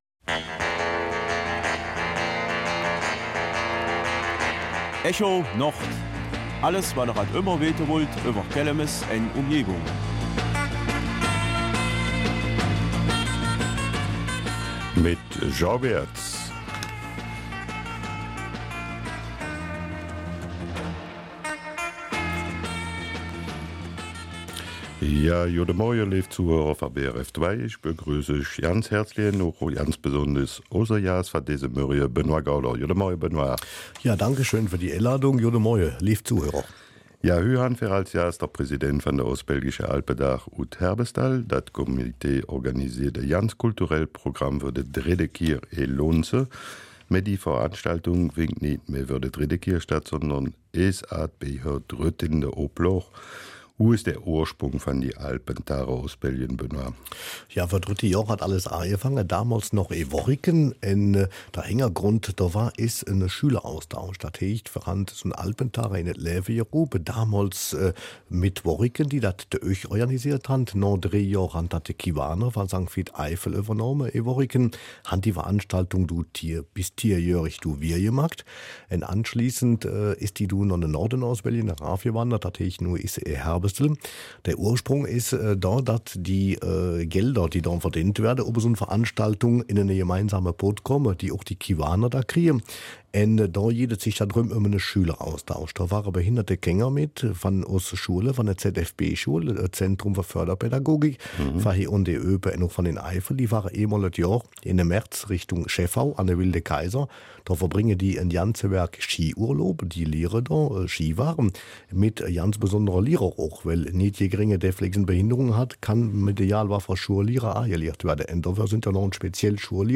Kelmiser Mundart: Alpentage Ostbelgien